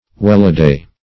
welladay - definition of welladay - synonyms, pronunciation, spelling from Free Dictionary Search Result for " welladay" : The Collaborative International Dictionary of English v.0.48: Welladay \Well"a*day\, interj.